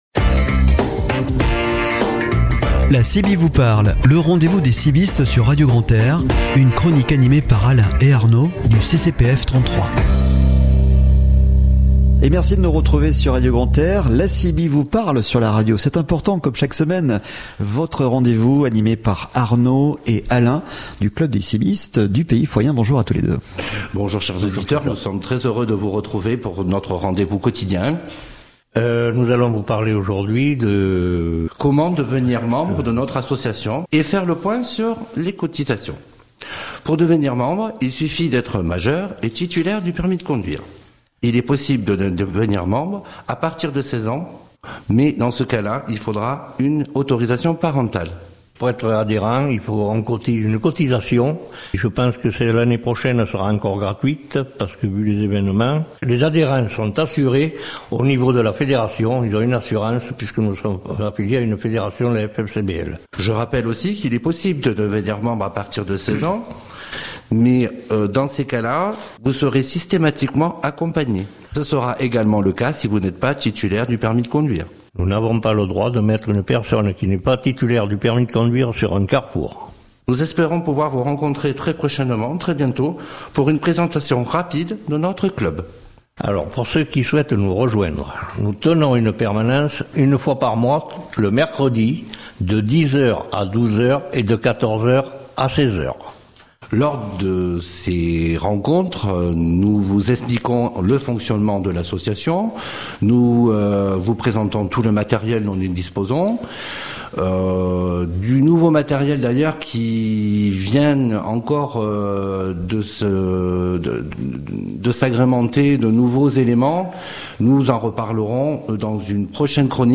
Le Podcast de la chronique "La CiBi vous parle" ! Jeudi 27 Octobre 2022